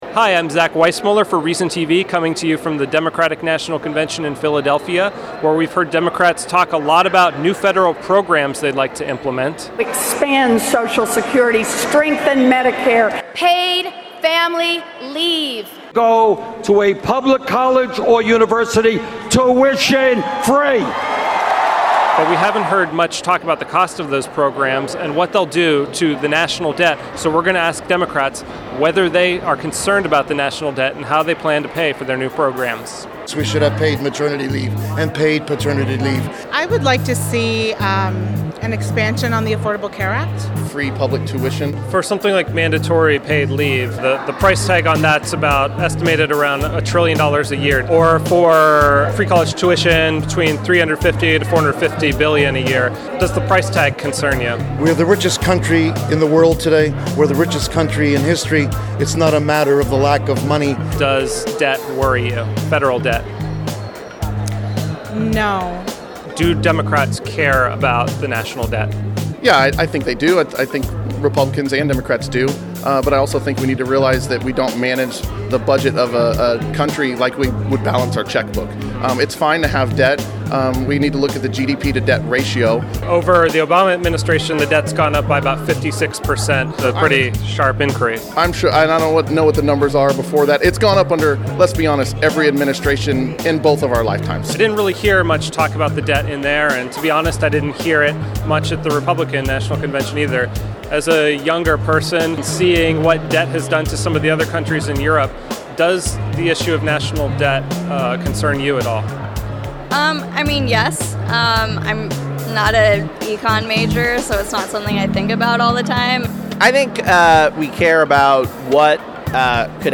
We asked them at the DNC.
Reason TV caught up with Democratic delegates at the Wells Fargo Center in Philadelphia, PA during the DNC to find out what new federal programs they'd like to see, how they planned on paying for them, and if they were concerned about the country's growing national debt.